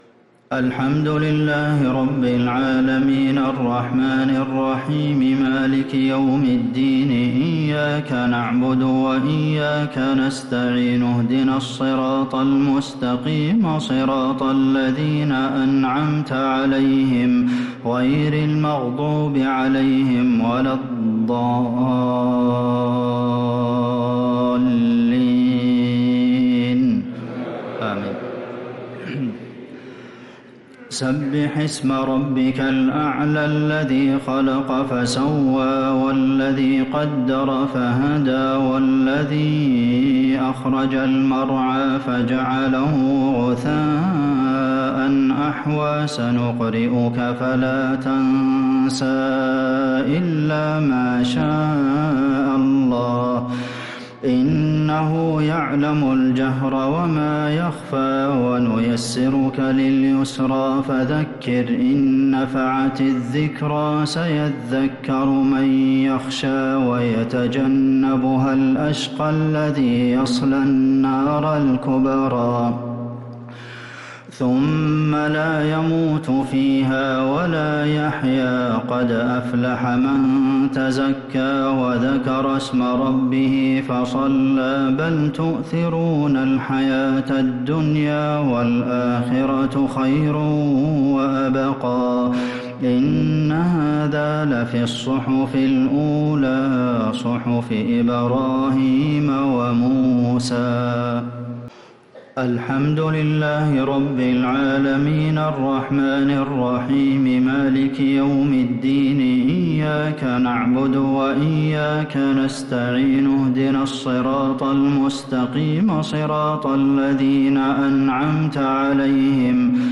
صلاة الشفع و الوتر ليلة 4 رمضان 1446هـ | Witr 4th night Ramadan 1446H > تراويح الحرم النبوي عام 1446 🕌 > التراويح - تلاوات الحرمين